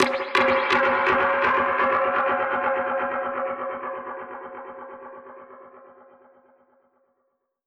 Index of /musicradar/dub-percussion-samples/125bpm
DPFX_PercHit_E_125-01.wav